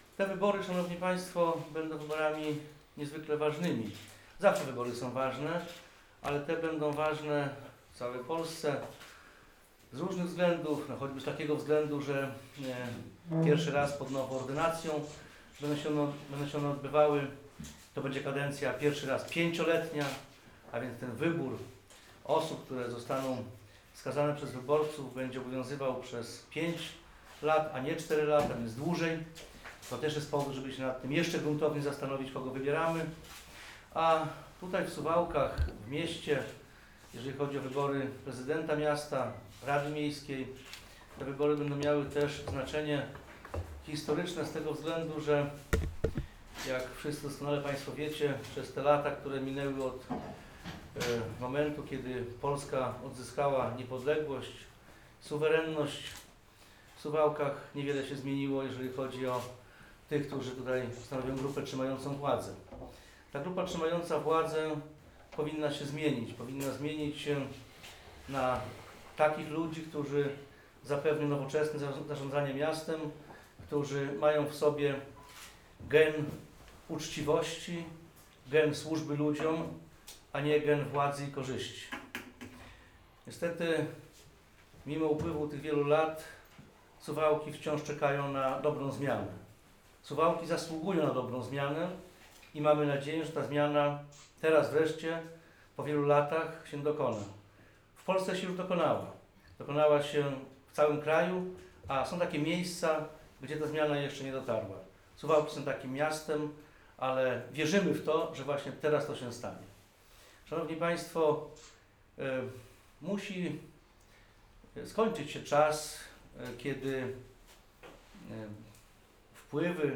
– Grupa trzymająca władzę w Suwałkach powinna się zmienić – przekonywał w poniedziałkowy (28 maja) poranek w swoim biurze poselskim wiceminister Jarosław Zieliński, prezentując kandydata PiS na prezydenta Suwałk.
Mówi Jarosław Zieliński, wiceminister MSWiA w rządzie PiS, poseł:
Jaroslaw_Zielinski_prezentacja_kandydataPiS.wav